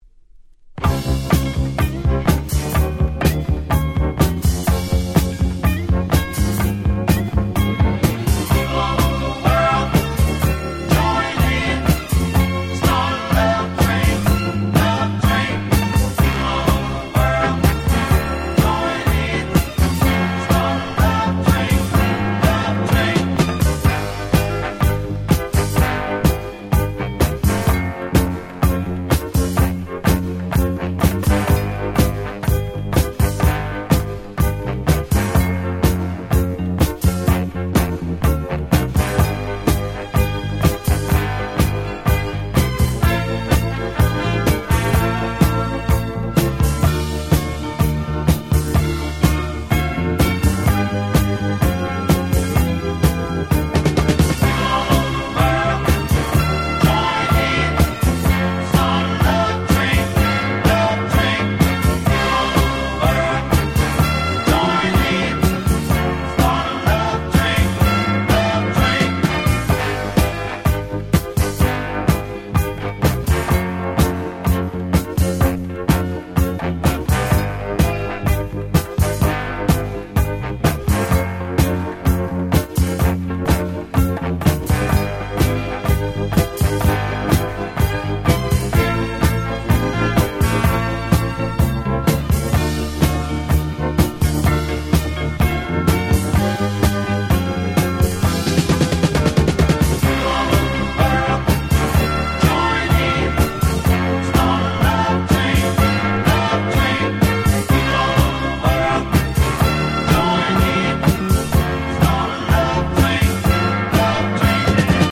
75' Super Hit Disco !!
70's ディスコ ダンスクラシックス Dance Classics